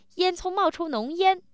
happy